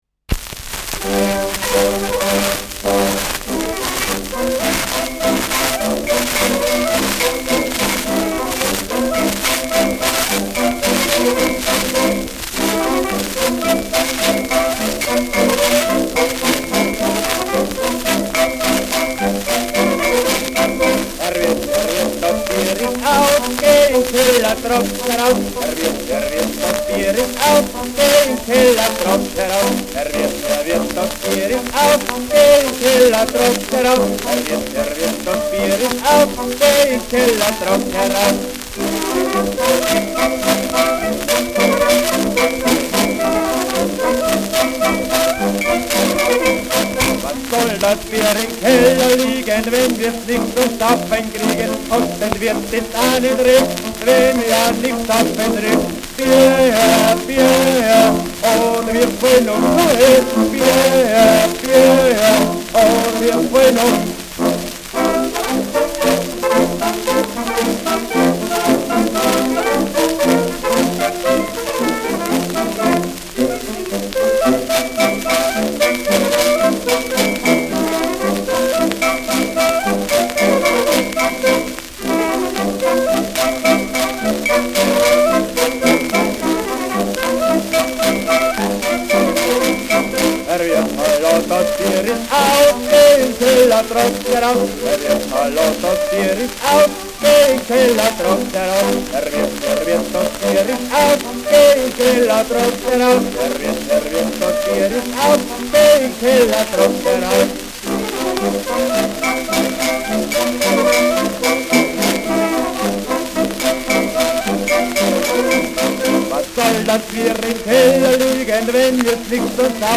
Schellackplatte
[Salzburg] (Aufnahmeort)